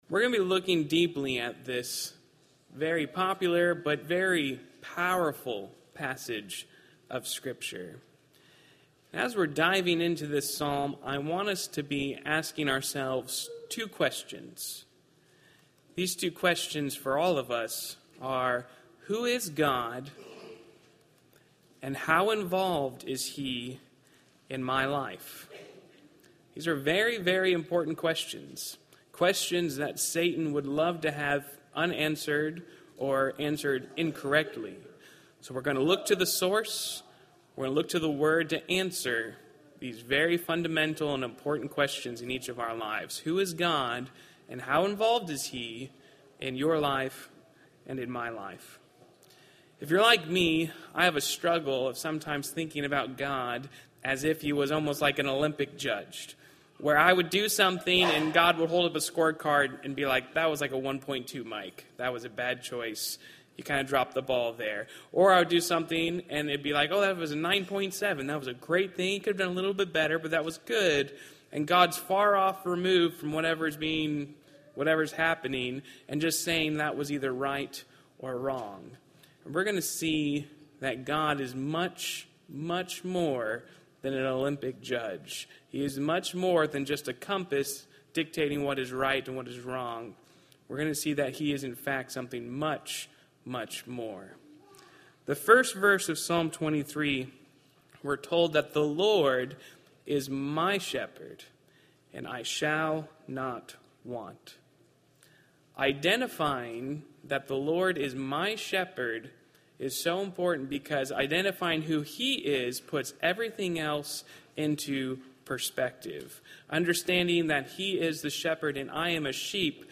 Valley church of Christ - Matanuska-Susitna Valley Alaska
Audio Sermons Who is God and How is He Involved in My Life?